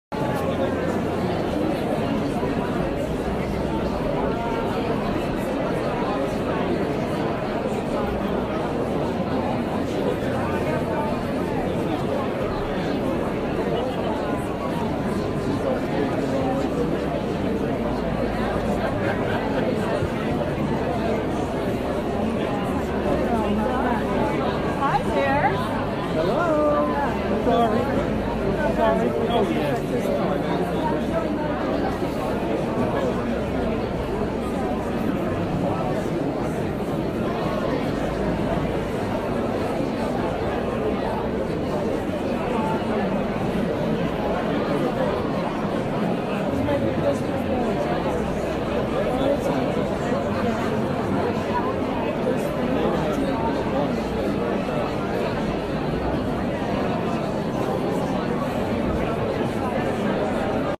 Download Theater sound effect for free.
Theater